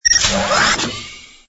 ui_equip_detach.wav